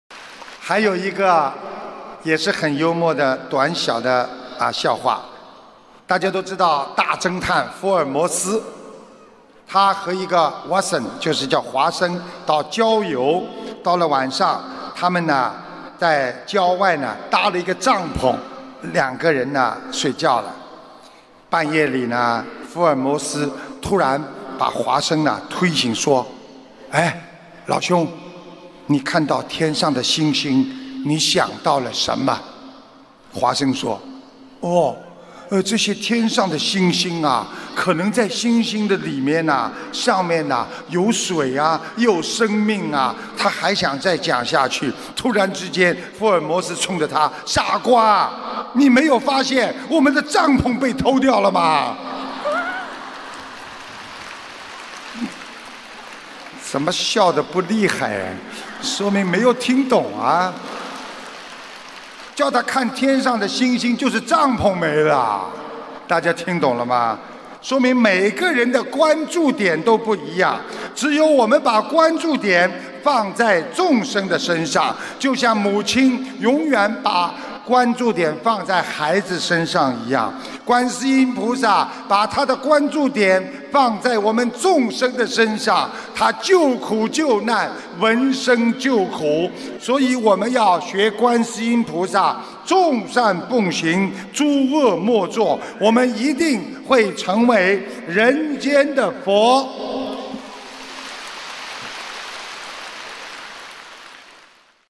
音频：睡觉睡到帐篷不见·师父讲笑话